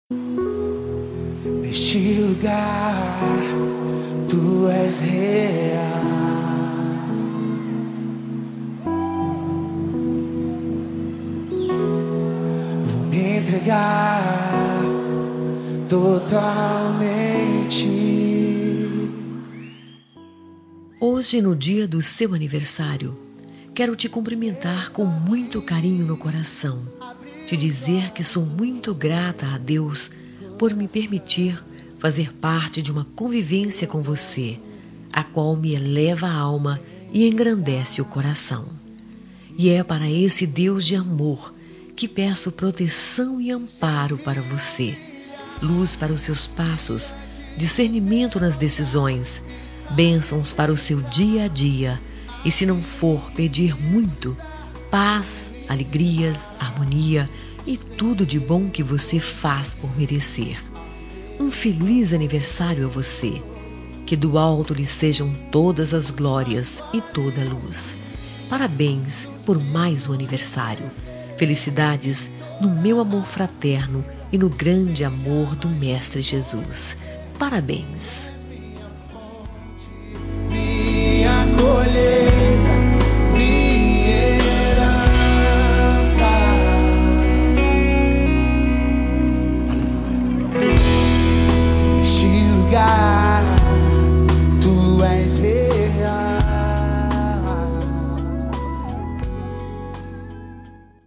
NEUTRA EVANGÉLICA
Voz Feminina